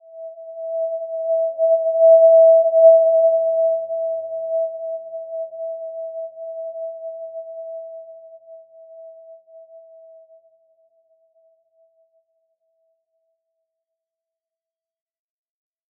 Simple-Glow-E5-p.wav